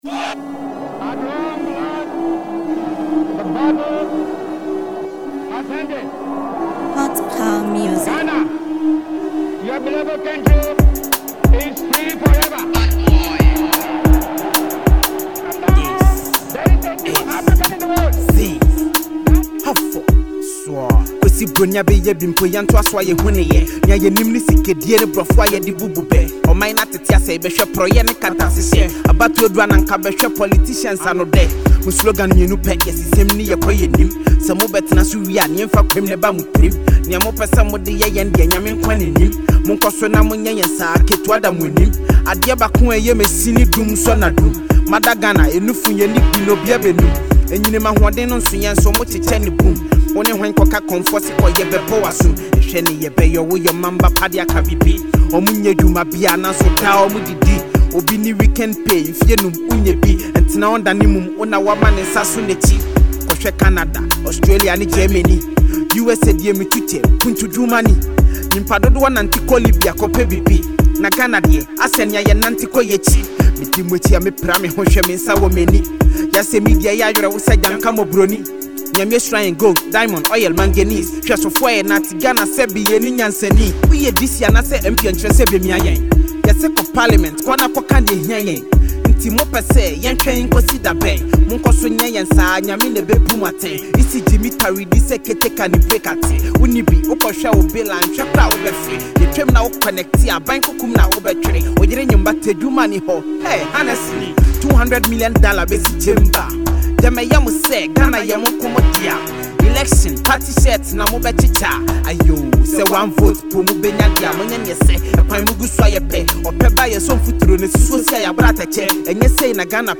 Ghanaian fast rising rapper and singer